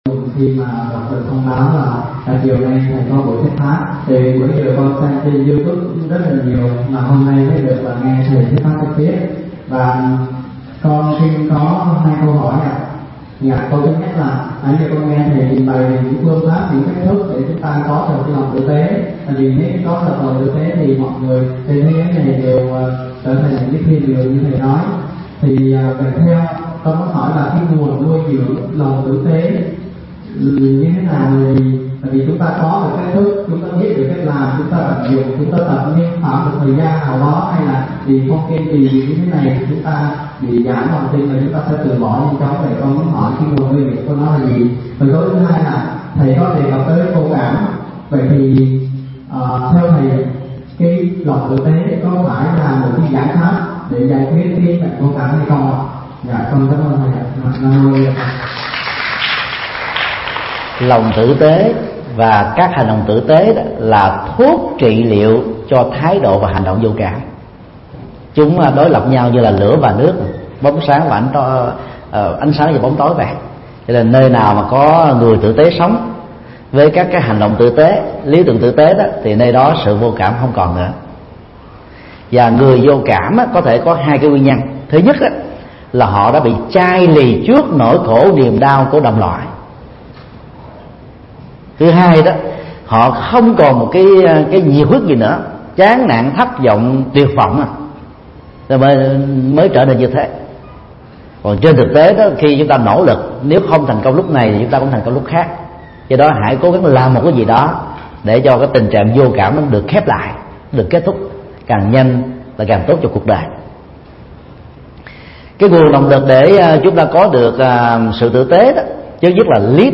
Vấn đáp: Khái niệm sự vô cảm